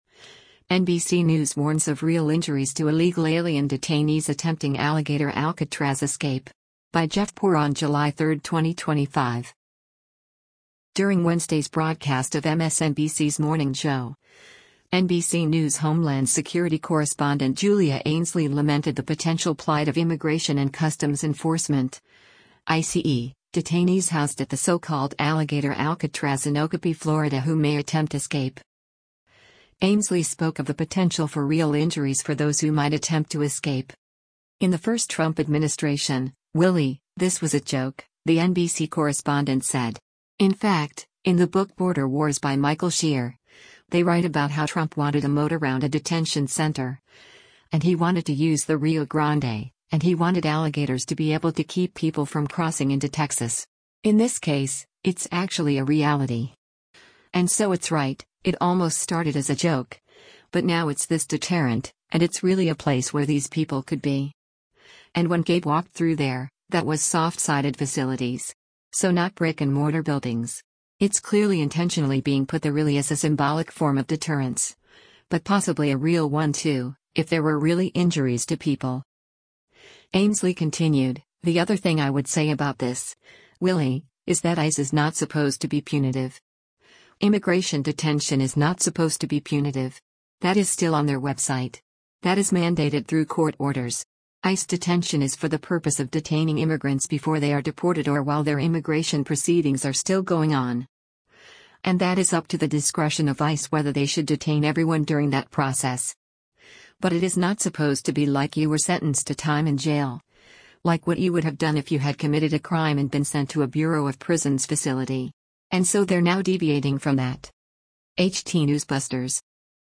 During Wednesday’s broadcast of MSNBC’s “Morning Joe,” NBC News homeland security correspondent Julia Ainsley lamented the potential plight of Immigration and Customs Enforcement (ICE) detainees housed at the so-called “Alligator Alcatraz” in Ochopee, FL who may attempt escape.